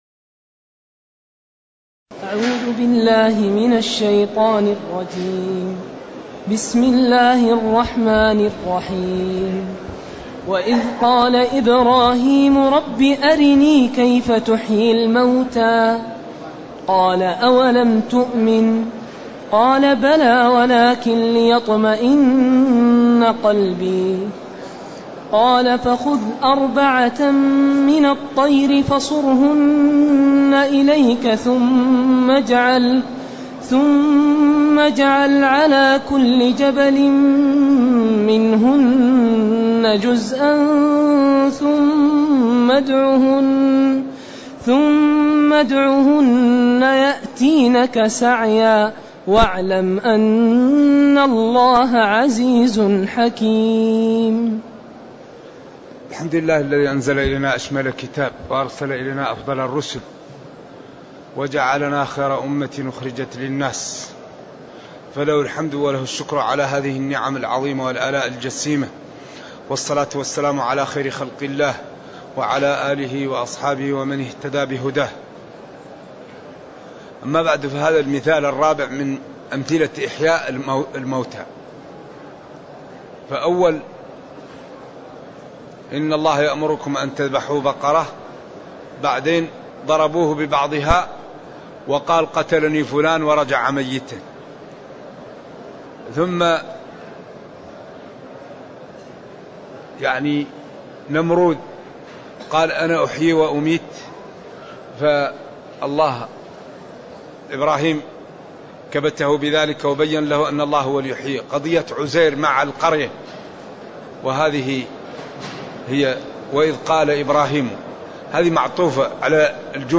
تاريخ النشر ٢٣ ذو القعدة ١٤٢٨ هـ المكان: المسجد النبوي الشيخ